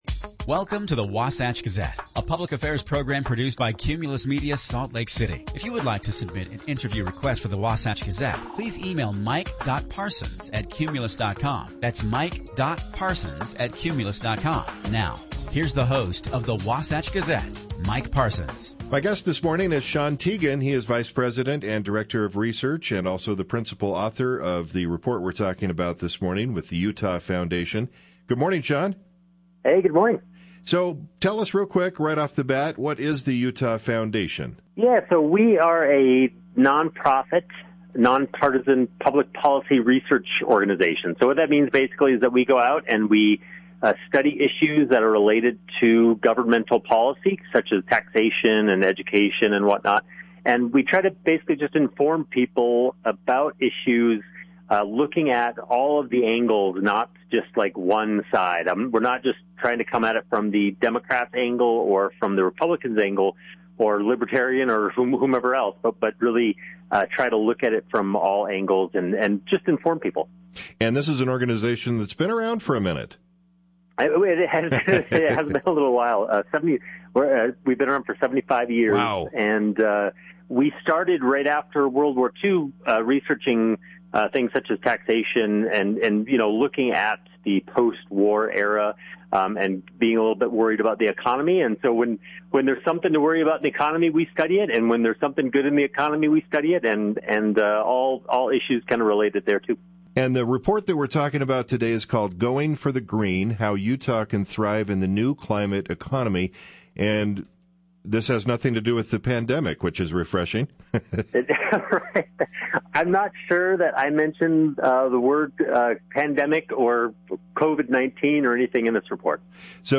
Going for the Green: How Utah Can Thrive in the New Climate Economy. The conversation was part of the Wasatch Gazette that plays on Sundays on: B98.7, KBER101, K-Bull 93.3, 860am, and Power94.9. Listen to the interview here: .